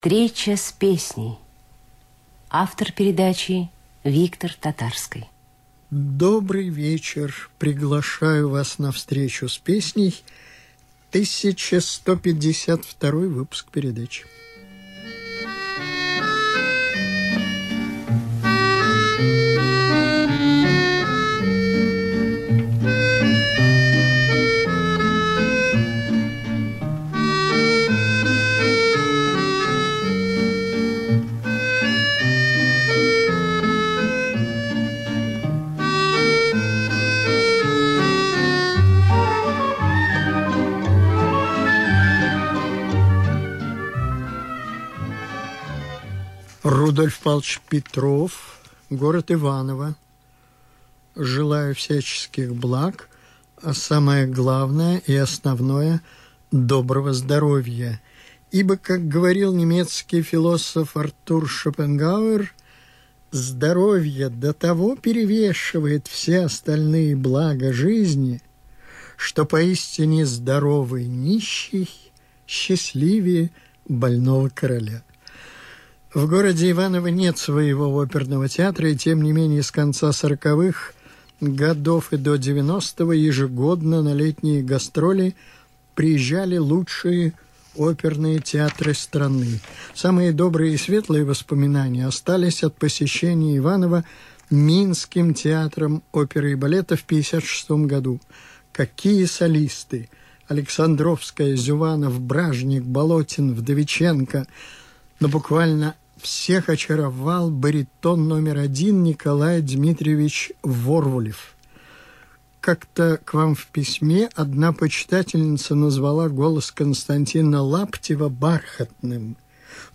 На Радио России этот выпуск в ужасном качестве, я даже не включил его поэтому в свою раздачу на трекере.